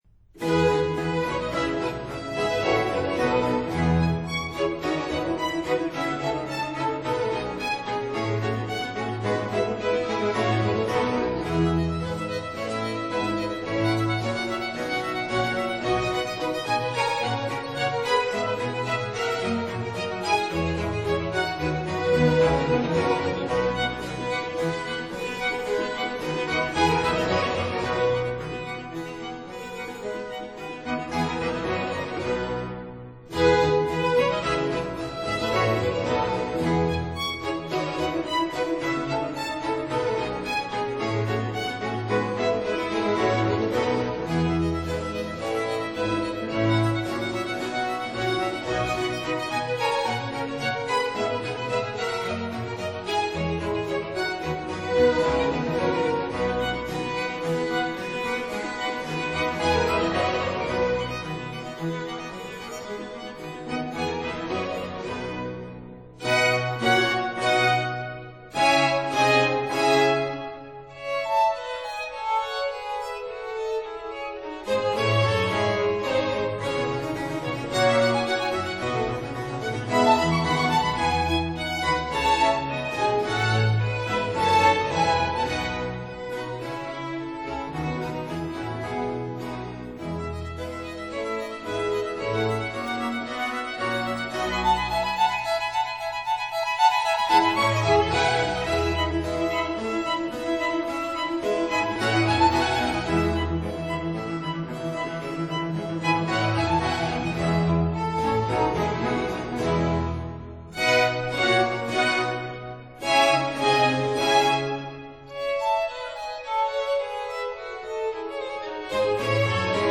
(01-07) Concerto grosso in D major, Op. 6, No. 1